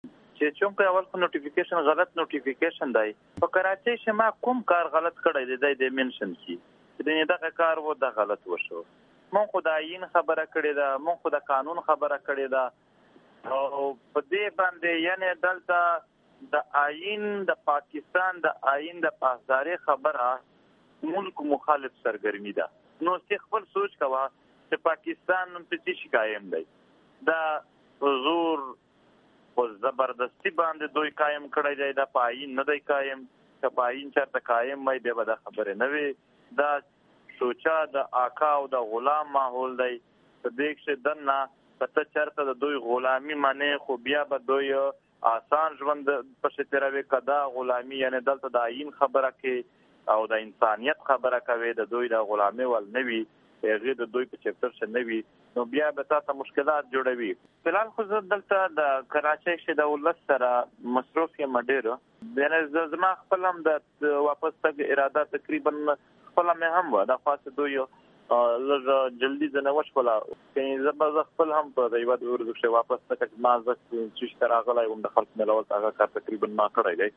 د پښتون ژغورنې غورځنګ مشر منظور پشتین د سند ایالت داخله محکمې لخوا د ۹۰ ورځو لګیدلي بندیز د امر وروسته په یوه لنډه مرکه کې وي او ای ډیوه ته ویلي، څوک چې د پاکستان ریاست غلامي نه مني ریاست يې ژوند ته نه پریږدي.
منظور پشتین دغه خبرې د کراچۍ ښار نه د وي او ای ډیوه سره په لنډو کې خبرو کې کړي.